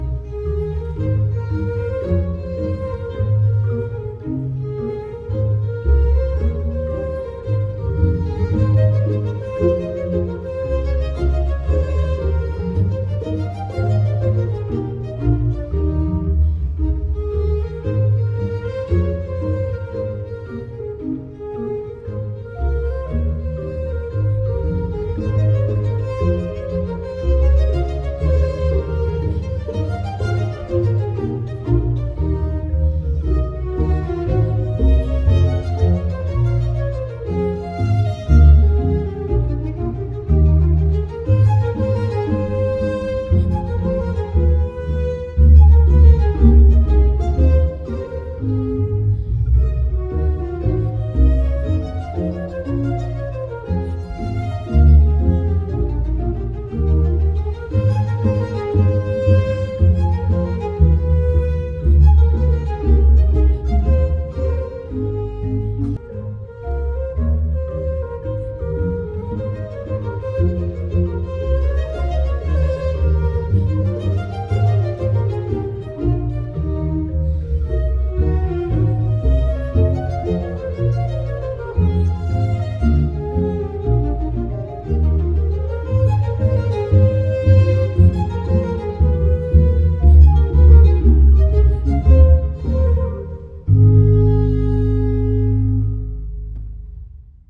BACH, cantae Bwv 30, aria alto - BRETON (Jules), glaneuse fatiguee.mp3